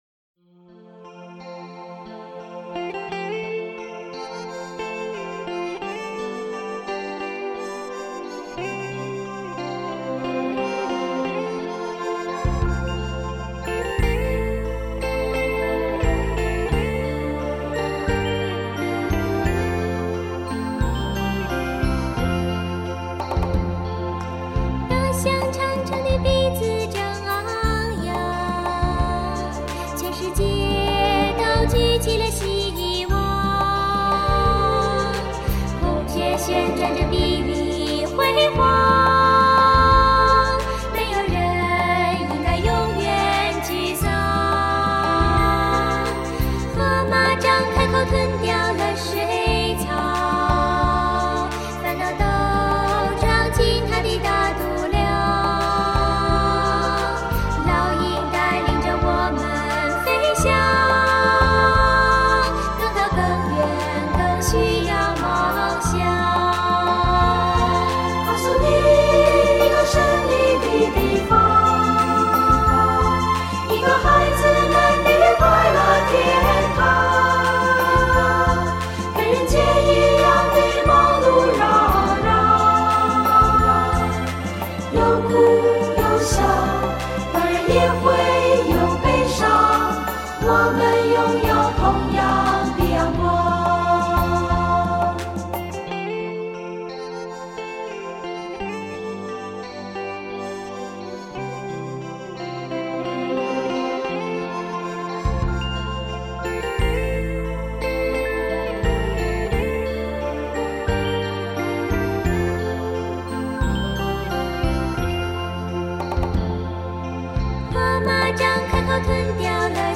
优美的和声